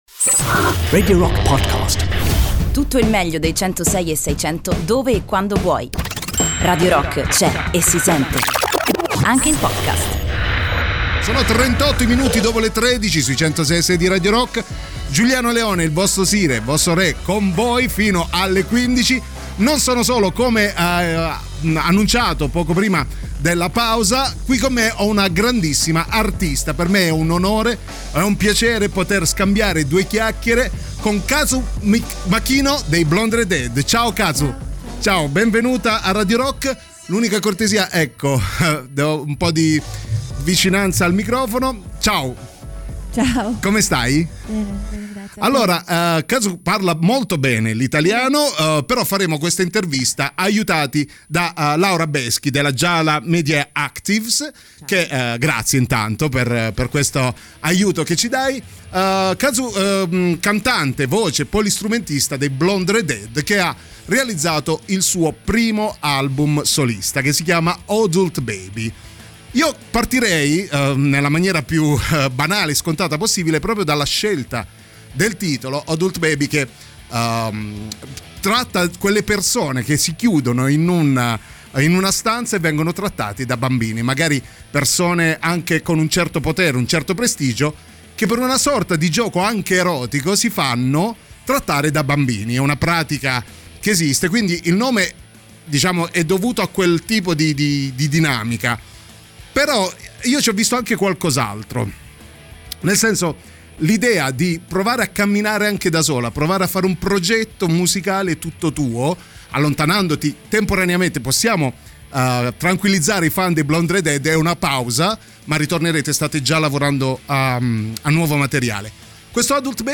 "Intervista": Kazu Makino (15-11-19)